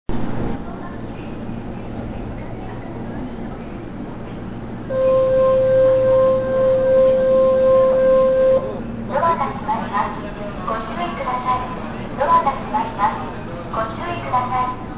・05系 車載ブザー
駅のブザーに似せたものですが、嘗ては非常にけたたましいブザーが用いられていました。
05buzzer.mp3